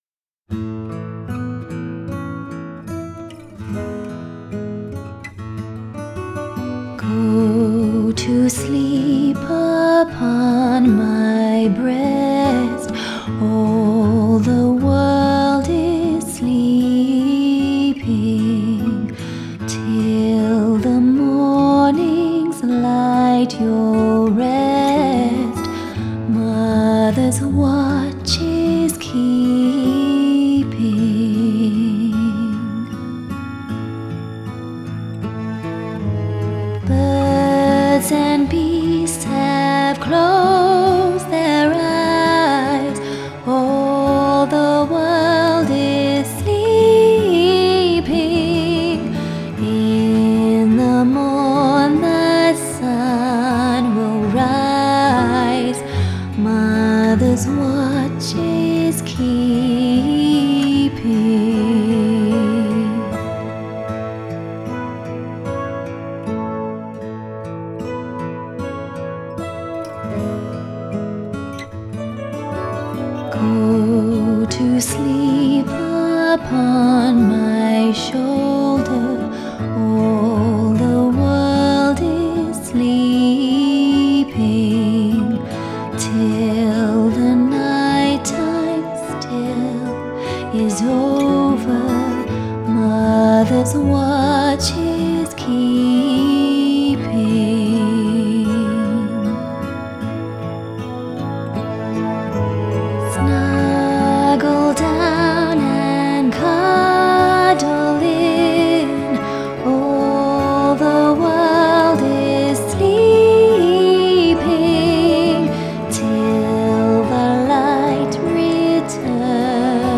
A gentle lullaby that wraps the whole world in quiet